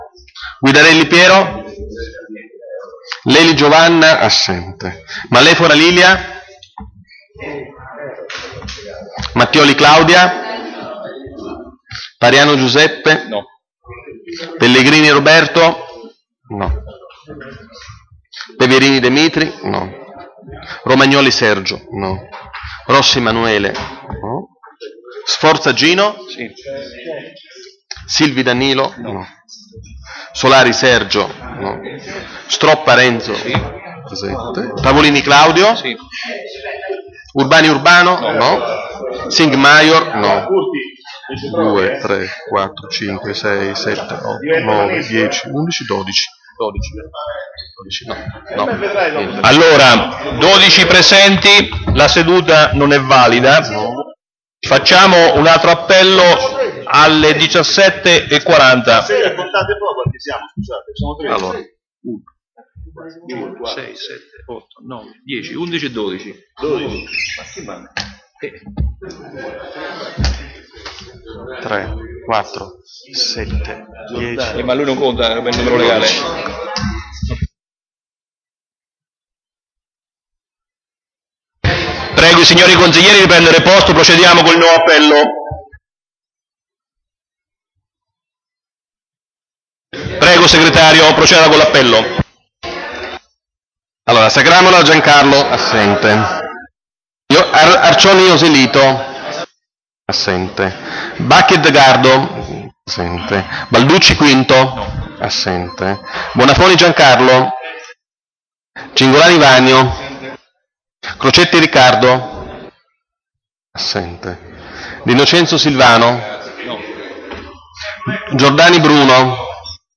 Ai sensi dell'art. 20, comma 7, dello Statuto Comunale e dell'articolo 14 del regolamento consiliare, il Consiglio Comunale è convocato giovedì 16 febbraio 2017 ore 17 presso la sala Consiliare dell'Unione Montana dell'Esino-Frasassi in via Dante n.268 a Fabriano